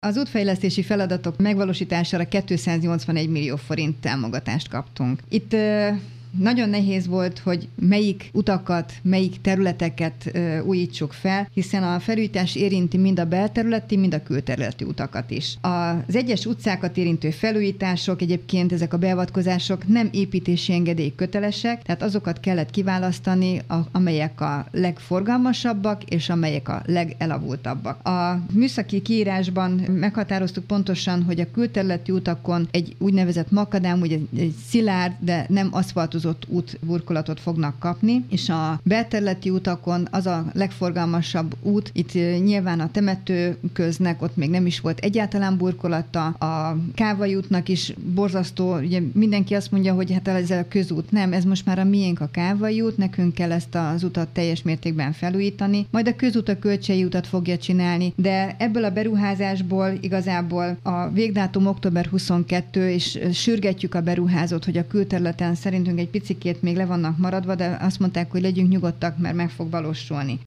281 millió forintos támogatást kapott Pilis útfejlesztésre. A beruházások érintik a bel- és külterületi utakat is. Hajnal Csilla polgármester arról beszélt, prioritási sorrendet kellett felállítaniuk a munkákhoz.